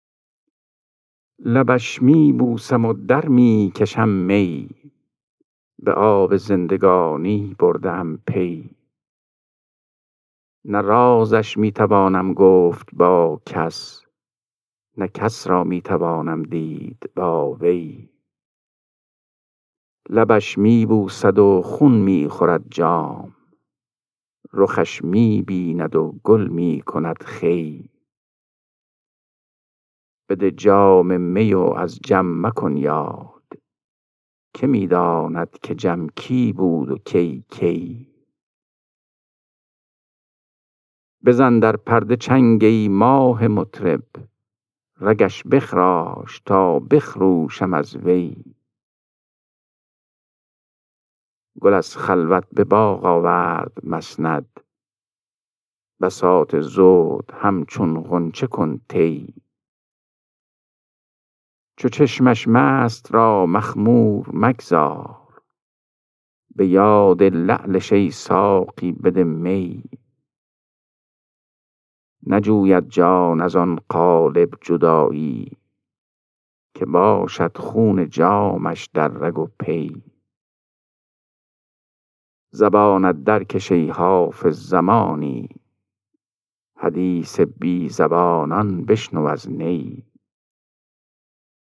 خوانش غزل شماره 431 دیوان حافظ